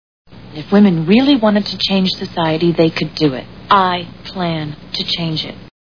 Ally McBeal TV Show Sound Bites